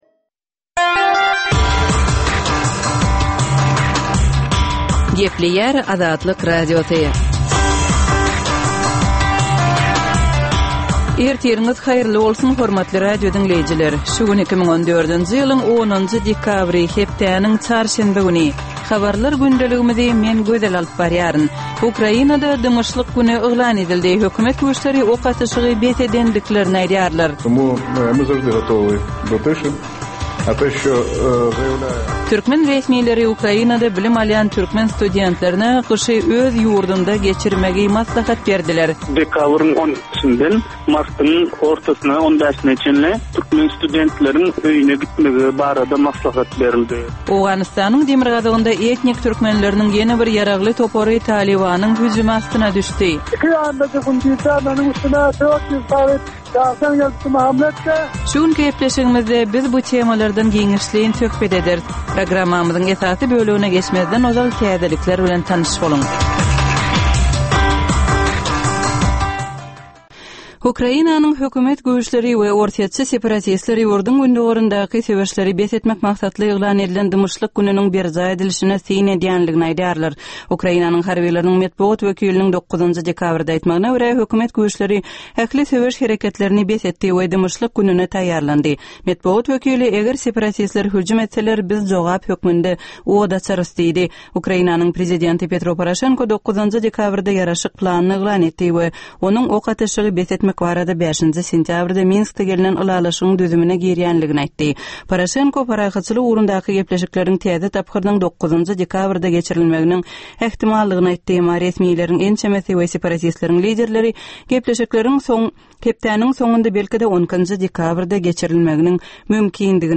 Dünýäniň dürli regionlarynda we Türkmenistanda şu günki bolan we bolup duran soňky wakalar barada gysgaça habarlar.